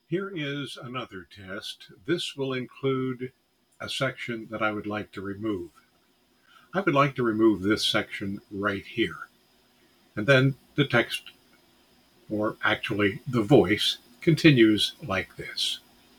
Then I used the application’s Enhance Speech filter:
The noise is gone, but the hard limiter seems to be a bit too aggressive. Still, the resulting audio is far better than the original.